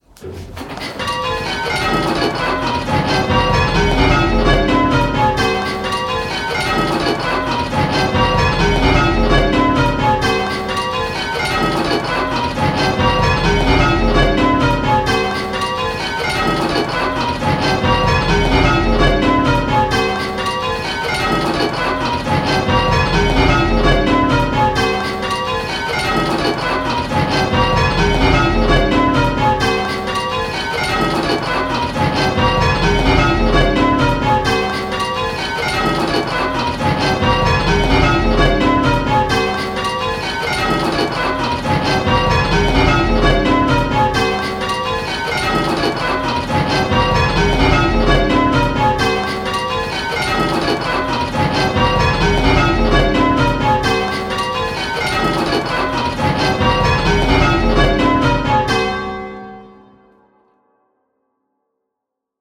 Listen to each clip and choose which bell is ringing early… the faults have been randomly placed, so there is no pattern and there might be consecutive clips that have the same fault!
Click the play button and listen to the rhythm of the 10 bells…. the rhythm will sound a bit lumpy!
Rounds-10-5E.m4a